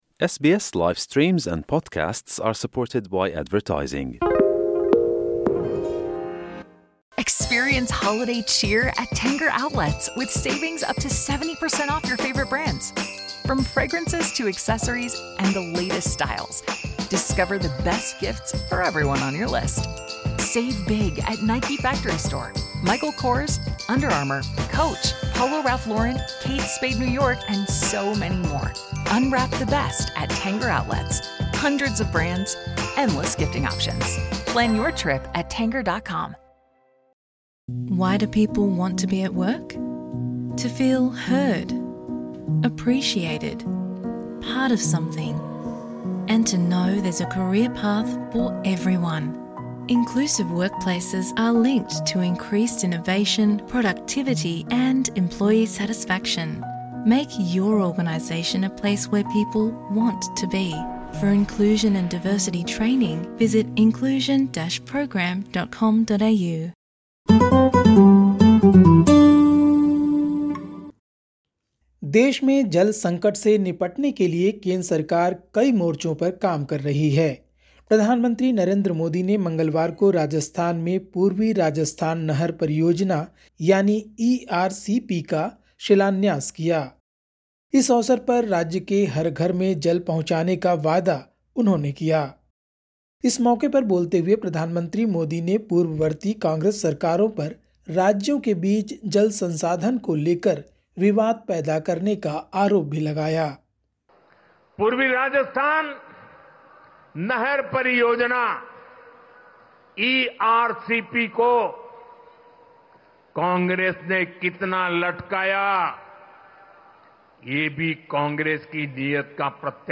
Listen to the latest SBS Hindi news from India. 18/12/2024